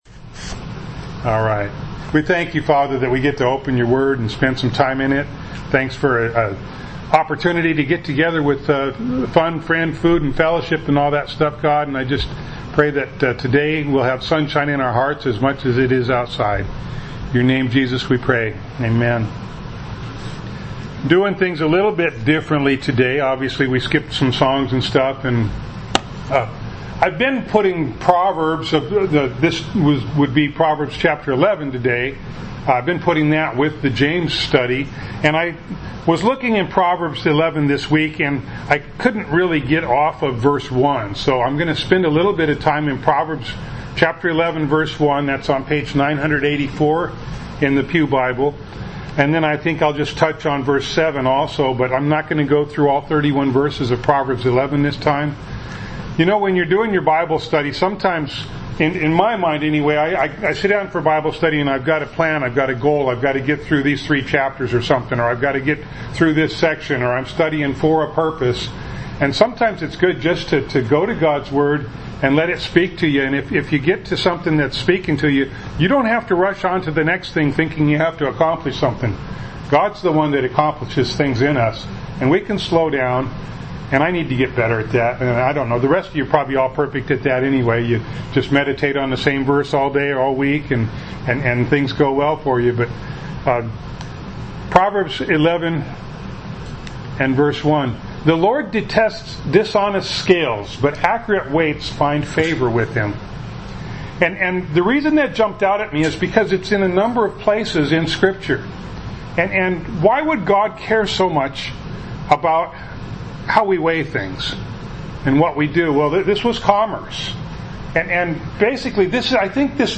James Passage: James 3:3-5a Service Type: Sunday Morning Bible Text